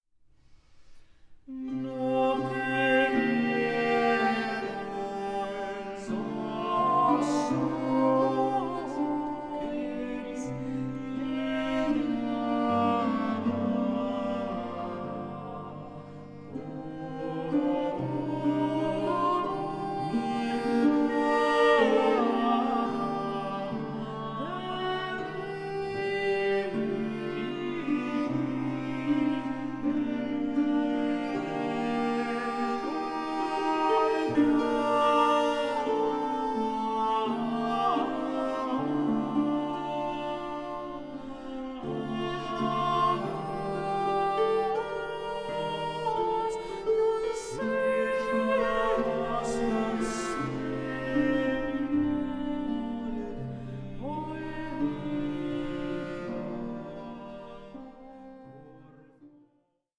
MELANCHOLIC SOLACE FOR TROUBLED TIMES